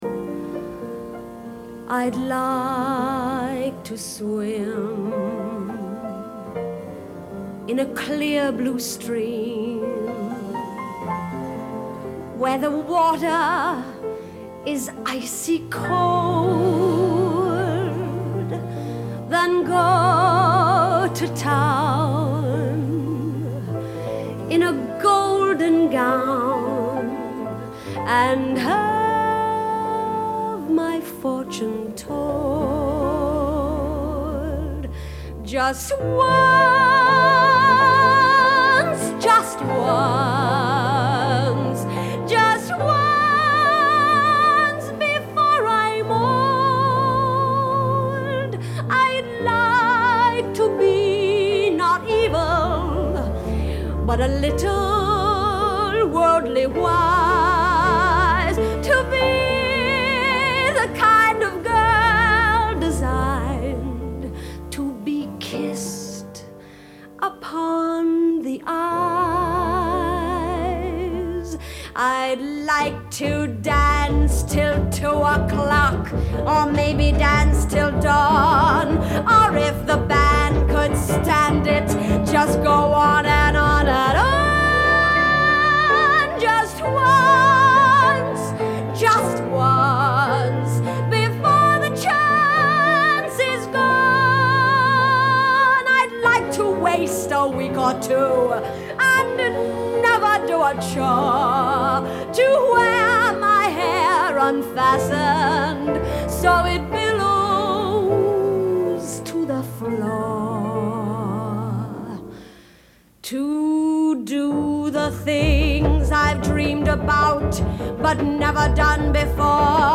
Genre : Pop